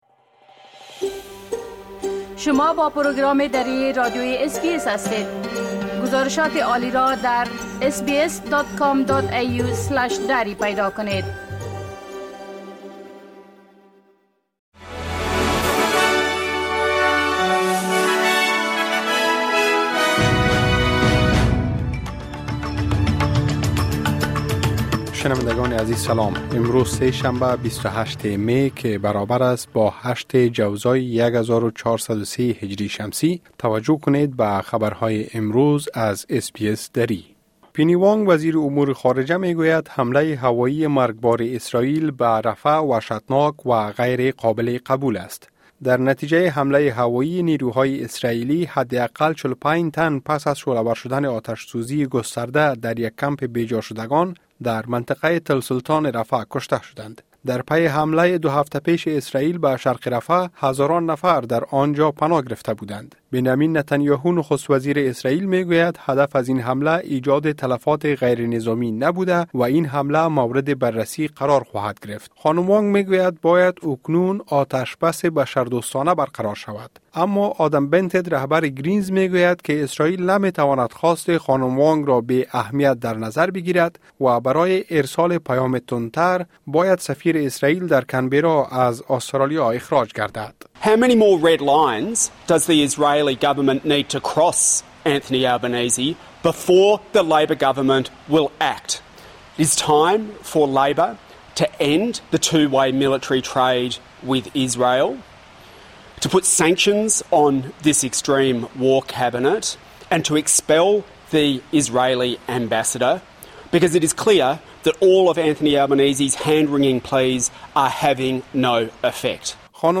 مهمترين اخبار روز از بخش درى راديوى اس بى اس|۲۸ می ۲۰۲۴